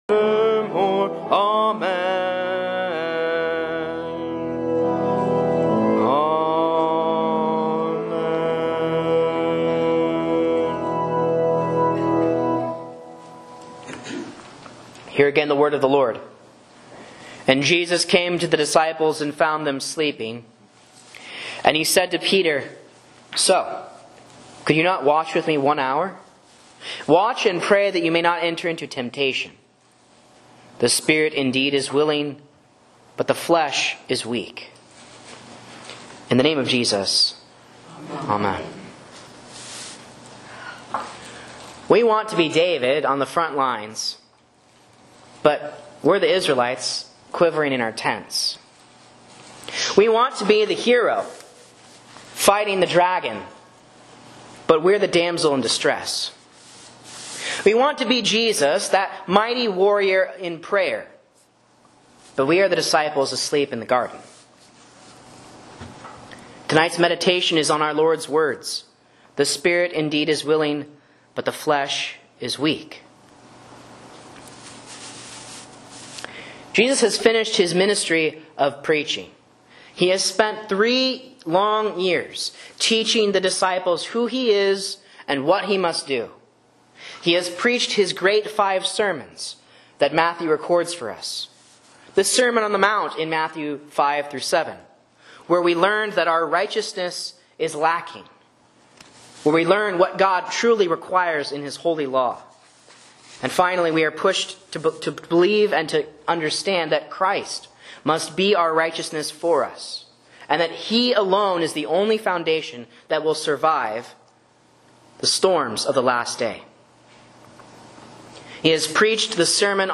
A Sermon on Matthew 26:40-41 for Lent Midweek